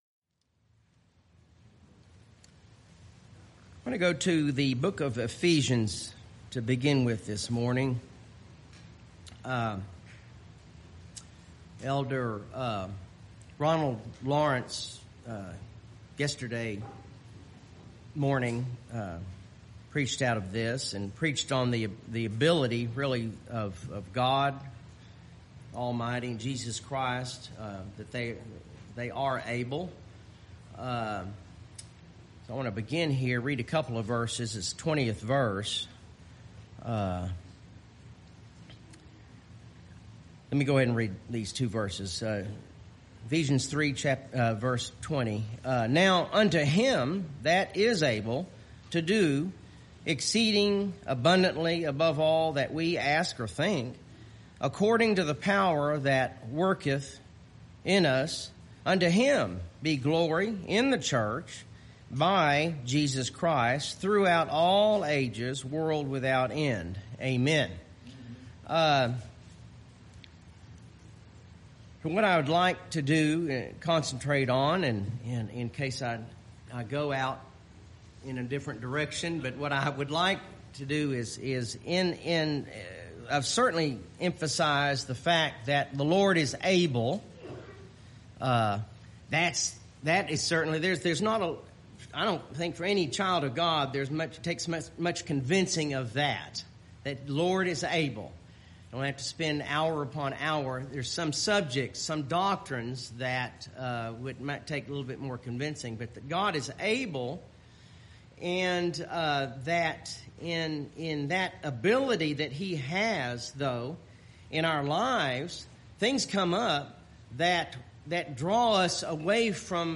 BHPBC Sermon podcast